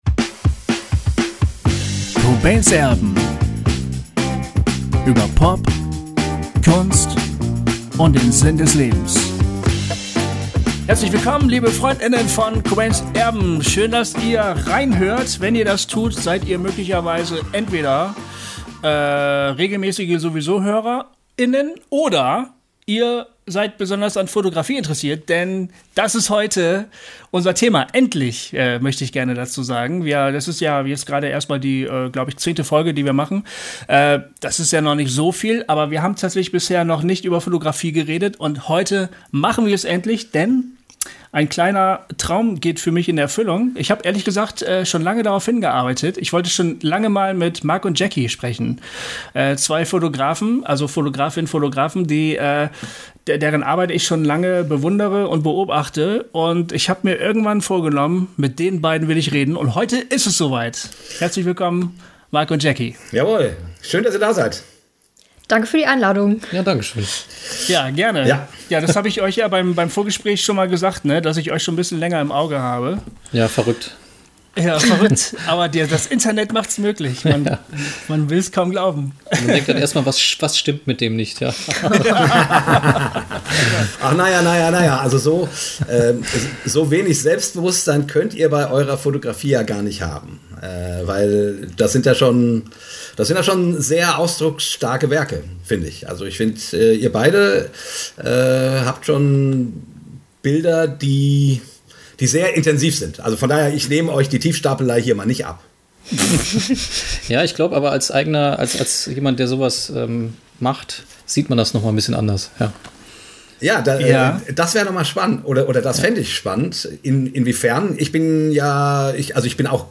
Ein Talk, auf den wir stolz sind.